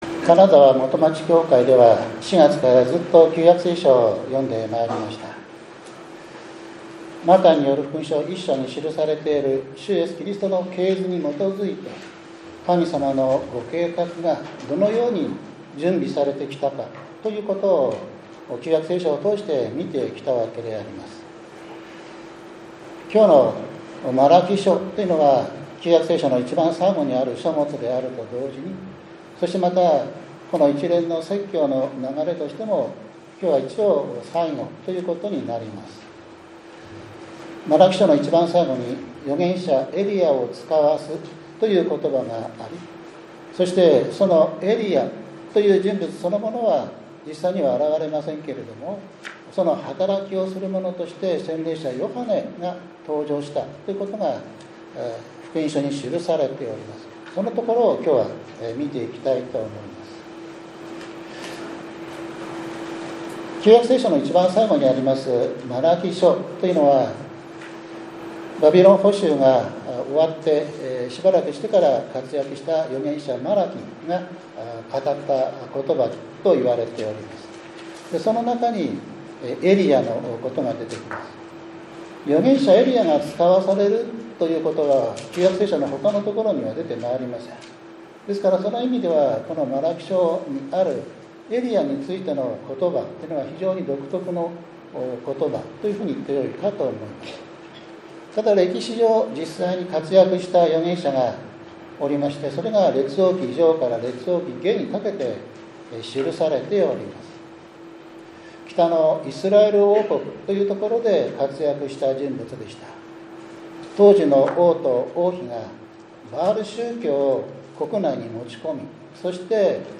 １２月１日（日）降誕節第１主日礼拝 マラキ書３章２３節～２４節 ヨハネによる福音書１章１９節～２８節